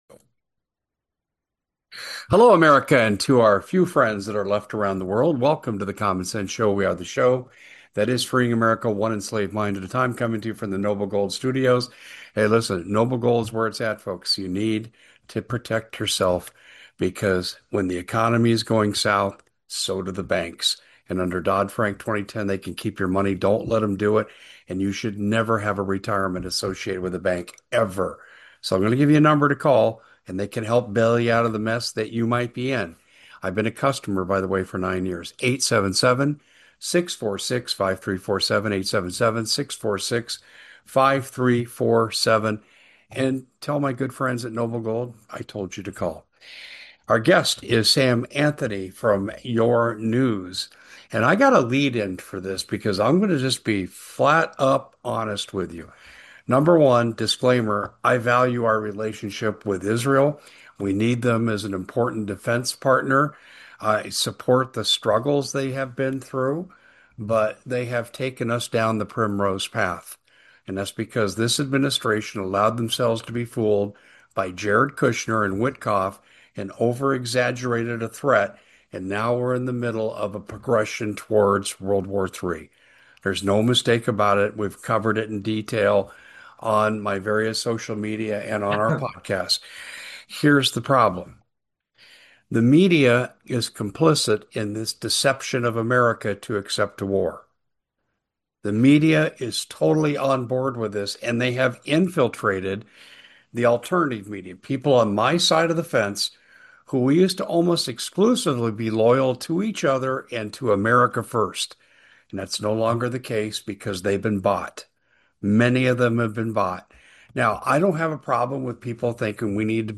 The Common Sense Show, hosted from Noble Gold Studios, discusses the current political climate, expressing concern over the potential for World War III and the media’s role in promoting war.